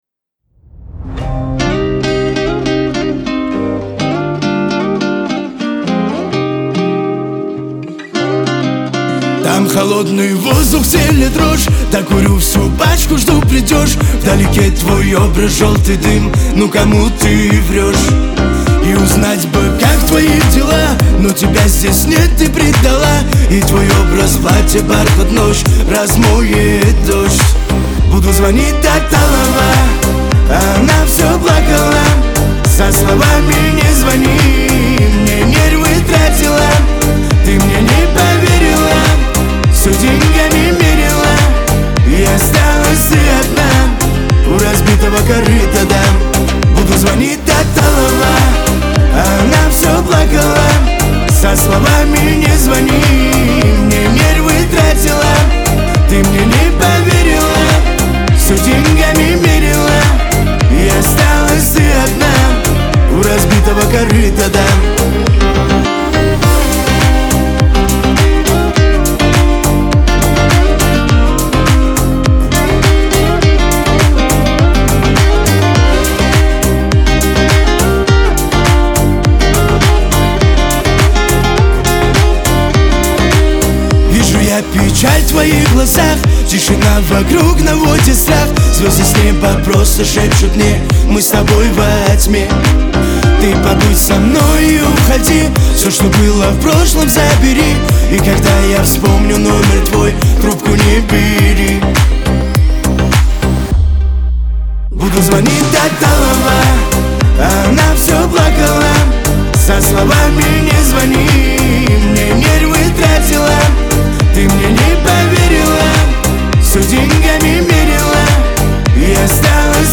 Кавказ – поп , грусть